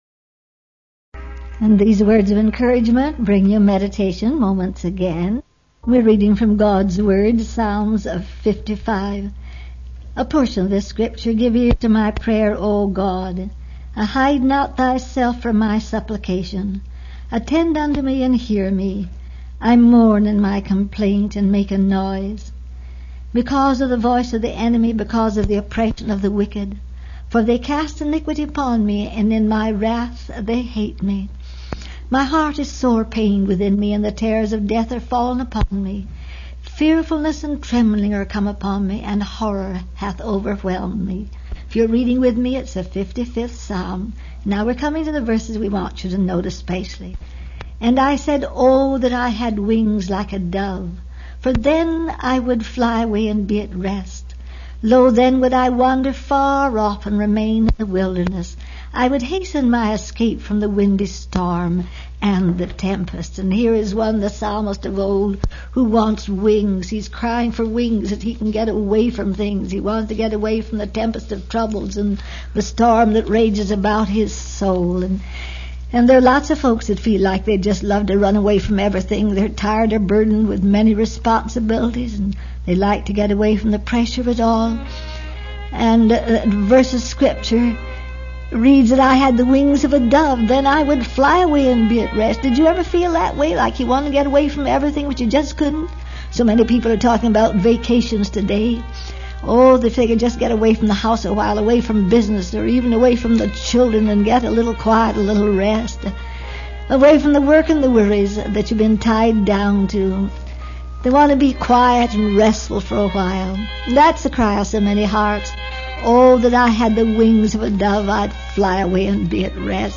This was a transcript of a Meditation Moments #51 broadcast.